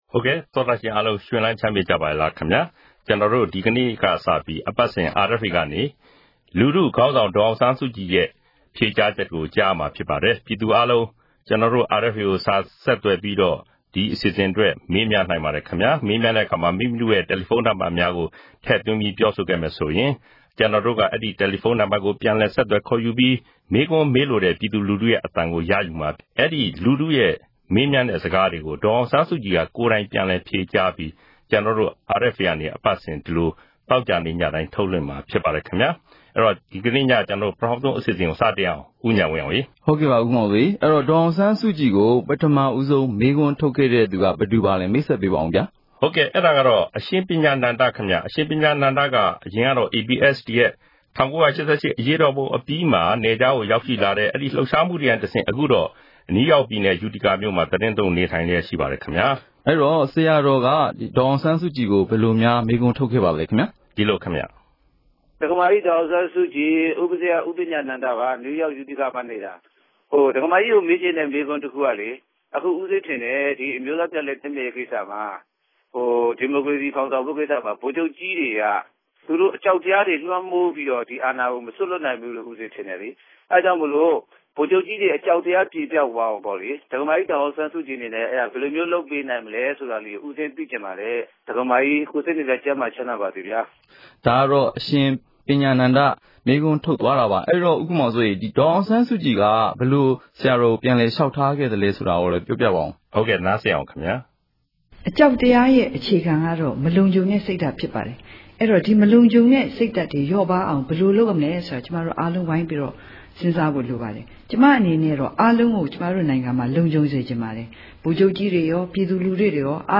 အဲဒီ တယ်လီဖုန်းနံပါတ်ကို အာအက်ဖ်အေက ခေါ်ယူ ဆက်သွယ်ပြီး ပြည်သူတွေရဲ့ မေးမြန်းစကားတွေကို အသံဖမ်းယူကာ ဒေါ်အောင်ဆန်းစုကြည်ရဲ့ ဖြေကြားချက်နဲ့အတူ ထုတ်လွှင့်ပေးမှာ ဖြစ်ပါတယ်။